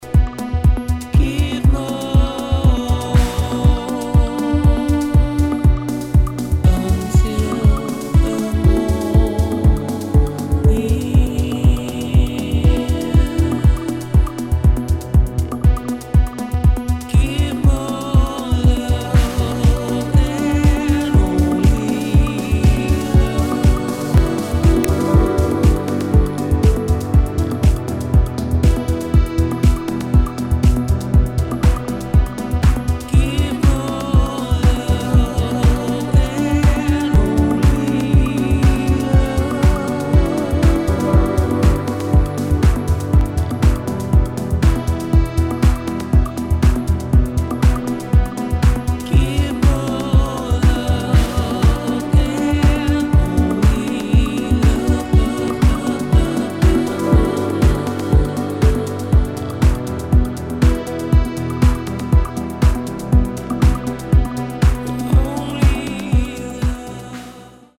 [ DEEP HOUSE | NU-DISCO ]